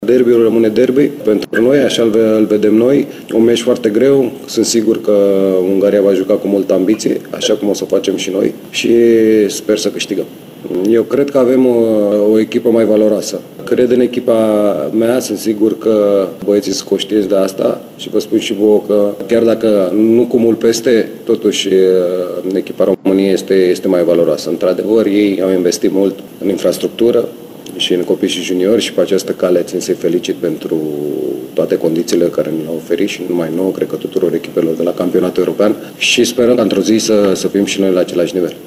Selecționerul Adrian Mutu se așteaptă la un meci al ambițiilor, de ambele părți, dar România poate face diferența cu un plus de valoare: